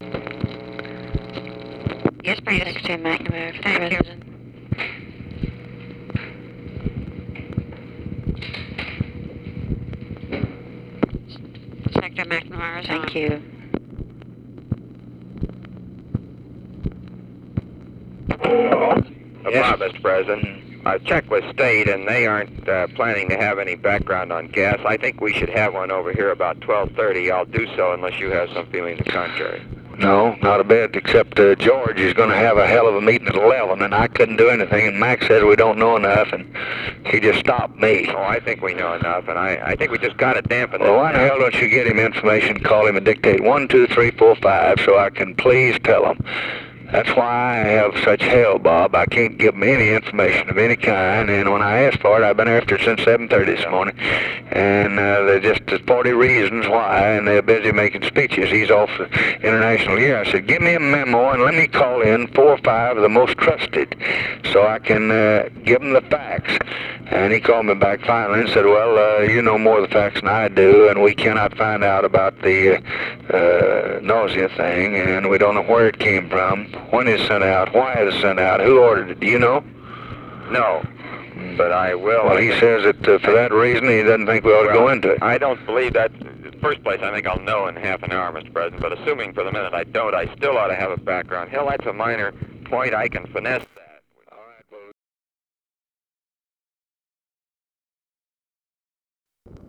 Conversation with ROBERT MCNAMARA, March 23, 1965
Secret White House Tapes